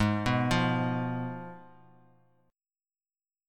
G#m6 chord